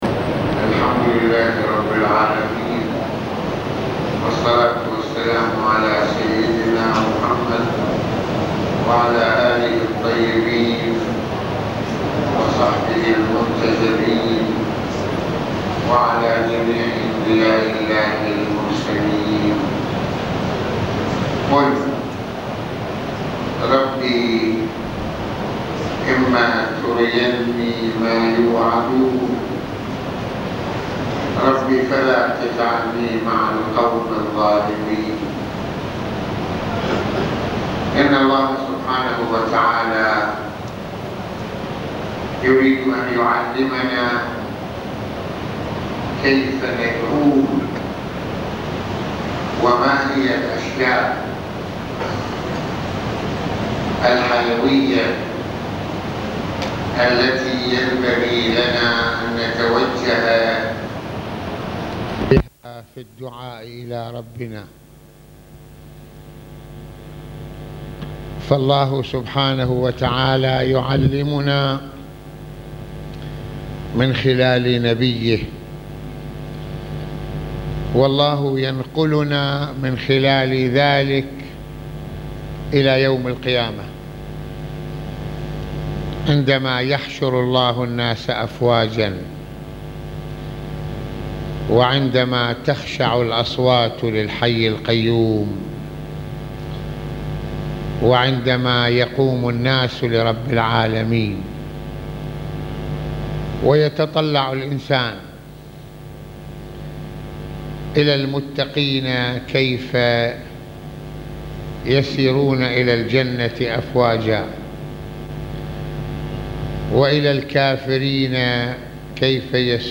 - يتحدّث العلامة المرجع السيّد محمّد حسين فضل الله(رض) في هذه المحاضرة عن الأمور الأساسية التي يربينا الله عليها في التوجّه والدعاء إليه ومن ذلك ظلم النفس بمعصيتها لربّها ونيلها لغضبه، وهنا الدعوة للناس ليعملوا في دنياهم بالخير لينالوا مرضاته، ويبتعدوا عن مصير الظالمين المستحق لسخطه..